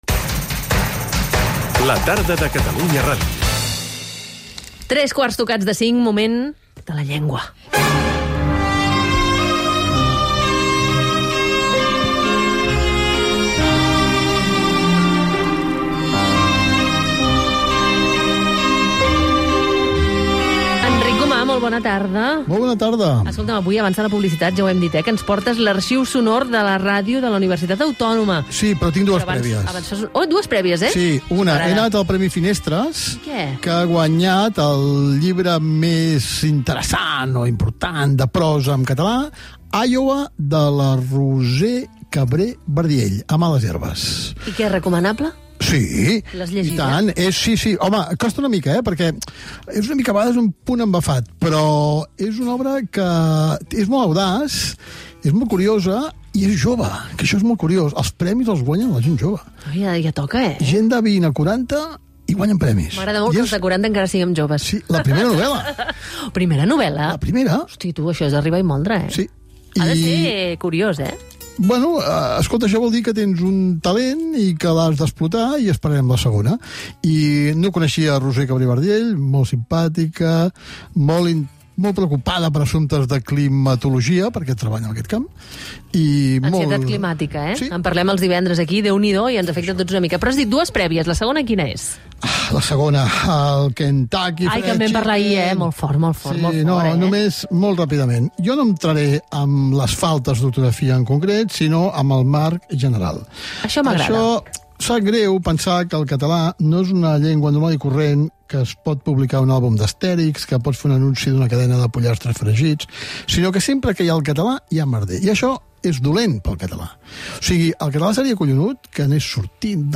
Premi Finestres de narrativa. Anunci en català de Kentucky Fried Chicken mal escrit. Fragments de l'Arxiu Sonor de la Ràdio a Catalunya de la Universitat Autònoma de Barcelona i comentari dels diferents estils de català. Gènere radiofònic Entreteniment